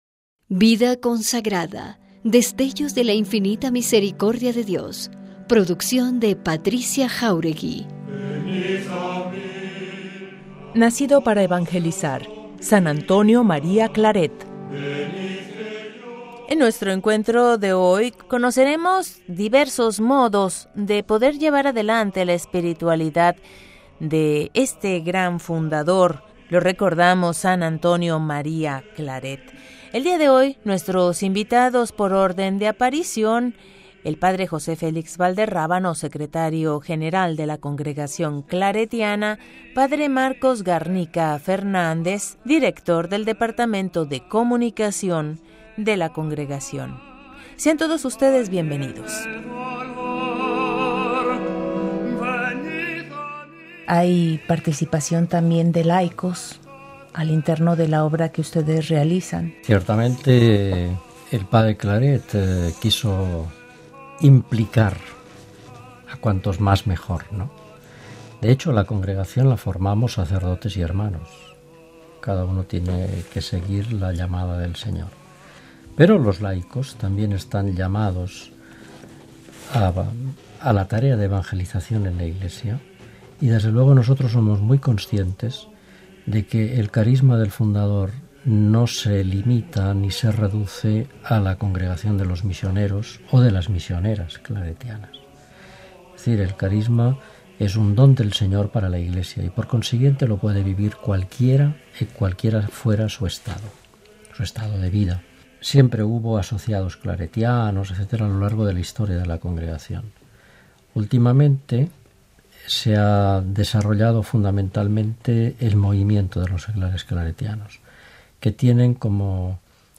** La música de la sigla y fondo: CD “Alzamos nuestra Voz”, Cantos tradicionales Misioneros Claretianos. Interpreta la Capilla de Música de la Catedral de Bilbao.